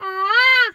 Animal_Impersonations
bird_peacock_squawk_soft_05.wav